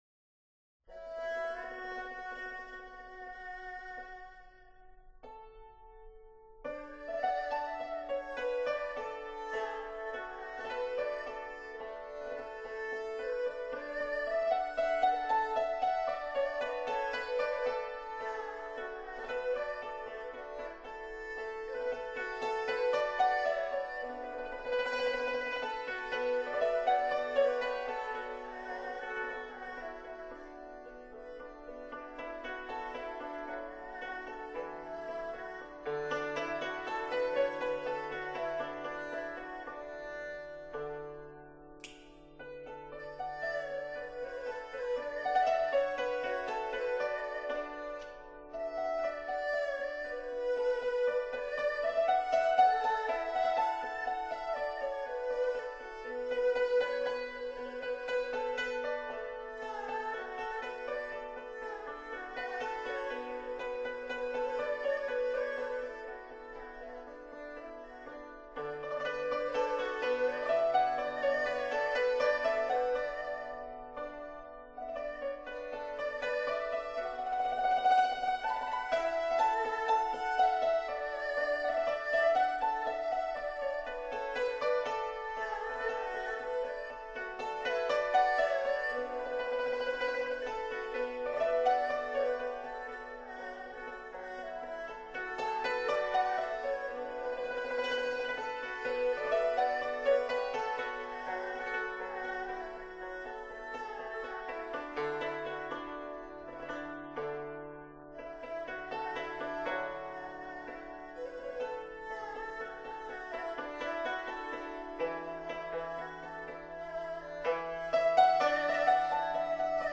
[2007-2-5] 杨琴与二胡《欢乐歌》